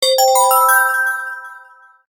Index of /phonetones/unzipped/Sony Ericsson/Xperia-X10/notifications
soft_bell.ogg